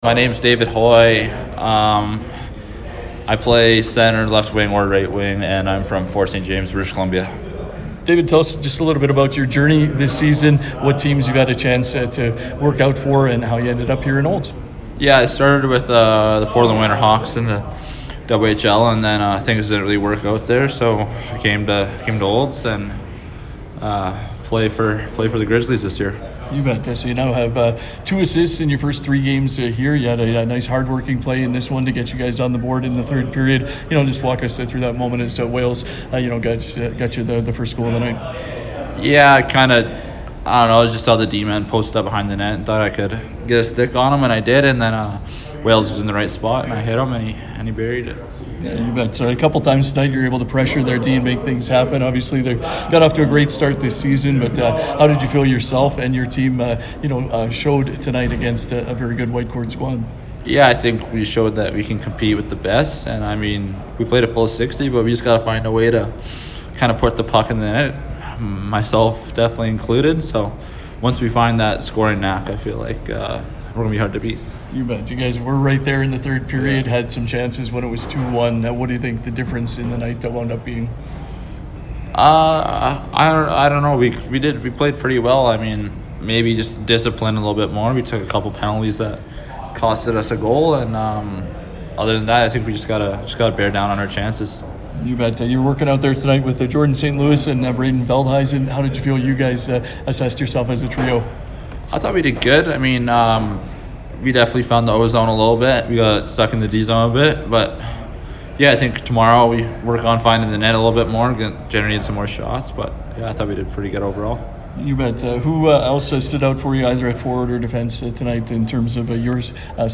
following Friday’s contest